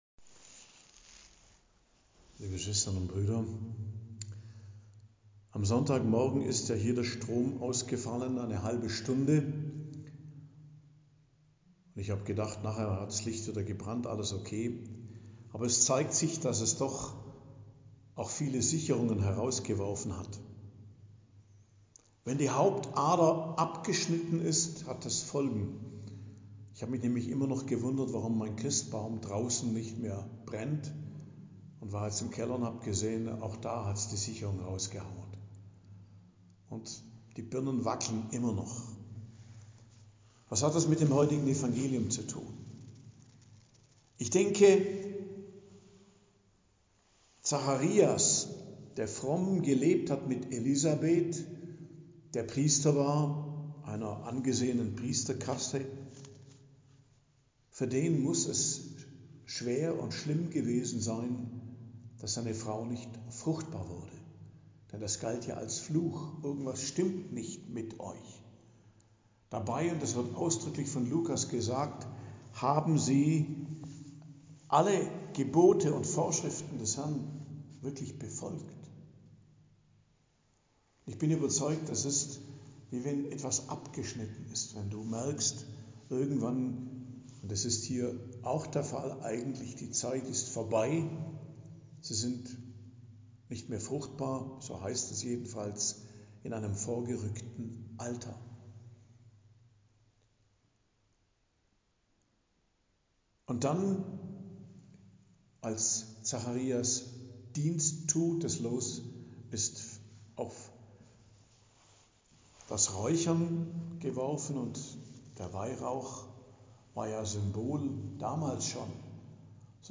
Predigt am Donnerstag der 3. Woche im Advent, 19.12.2024